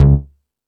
Hip House(33).wav